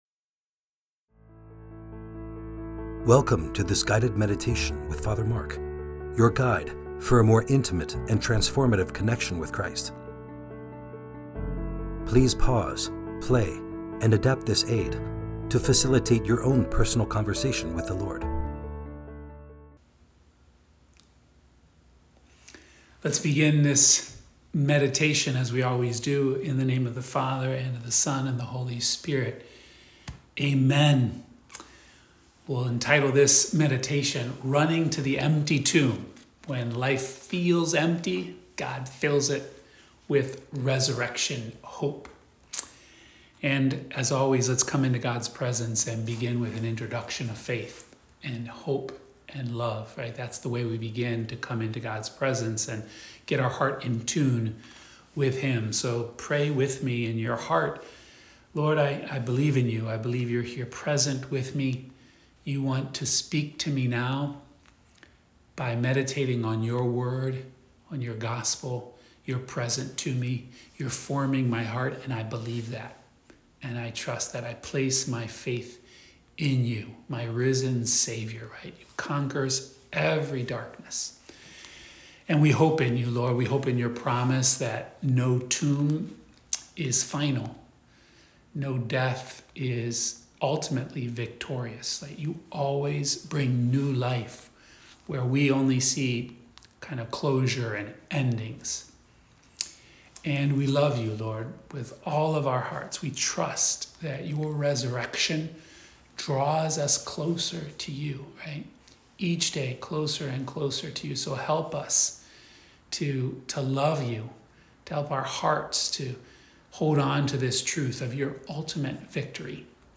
Friday Meditation